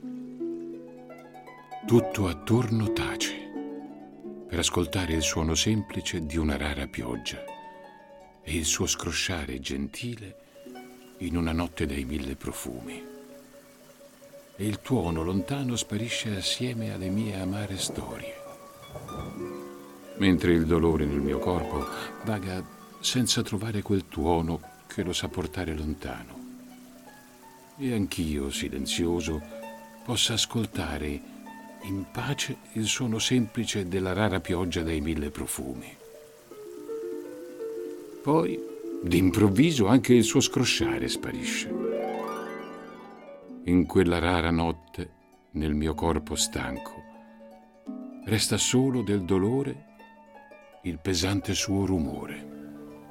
in una lettura tratta dalle poesie di Maurizio Tocchi.